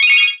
Sfx Gem Yellow Collected Sound Effect
sfx-gem-yellow-collected.mp3